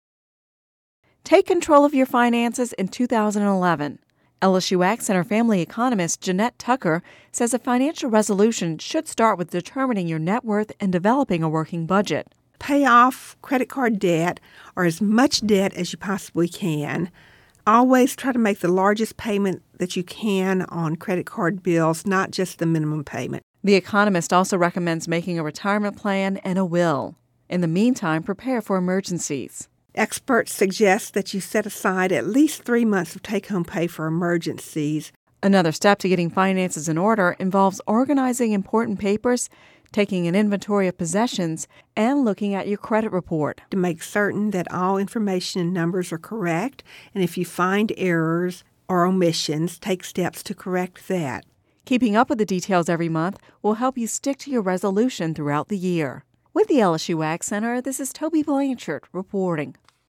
(Radio News 01/03/11) Take control of your finances in 2011.